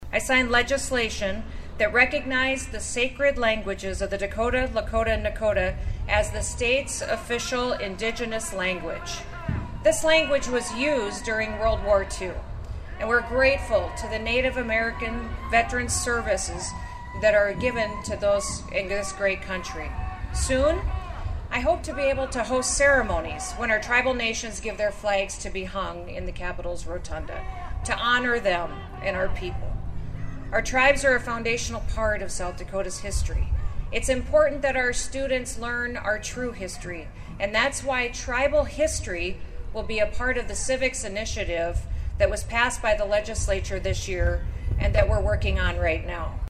Singing, dancing, praying, praising and protesting all took place on the lawn of the South Dakota State Capitol in Pierre today (May 20) during the Governor’s Round Dance event.
Governor Kristi Noem said she’s worked to celebrate the state’s tribes during her time in office.